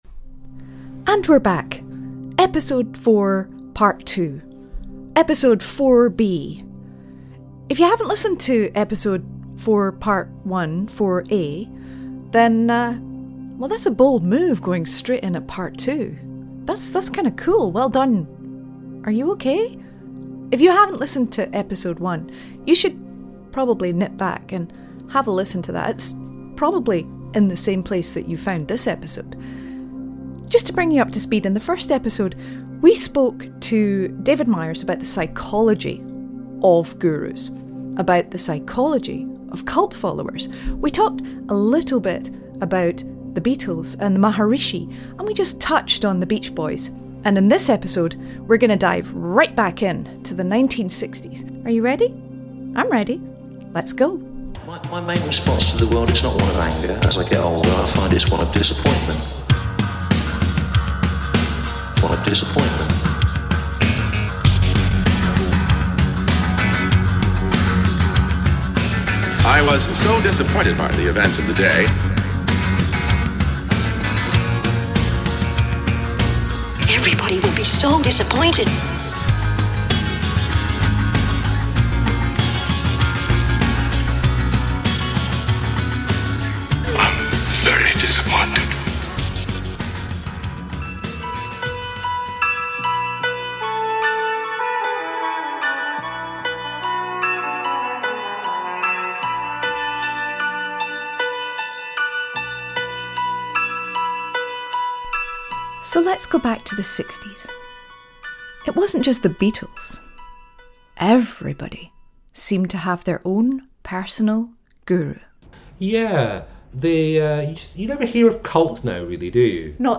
Clips used in this episode Mike Love's speech at the 1988 Rock and Roll Hall of Fame ceremony